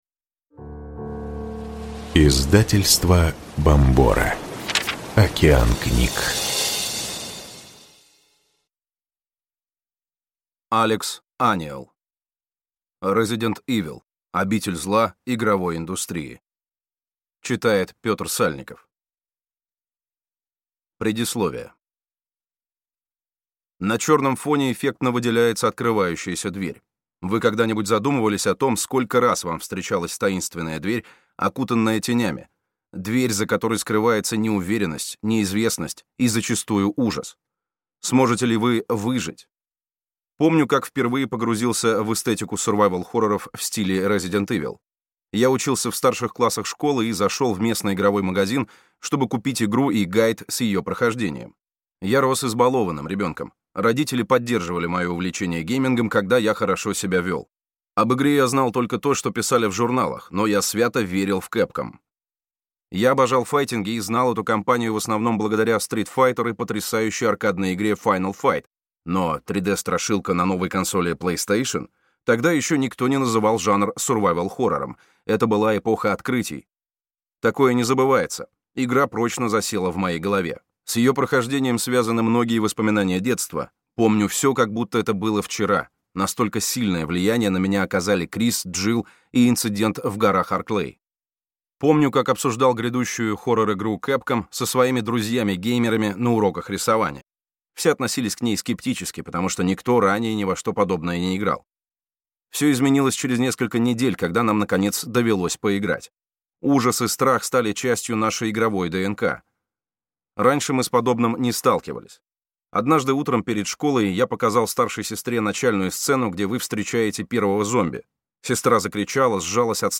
Аудиокнига Resident Evil. Обитель зла игровой индустрии | Библиотека аудиокниг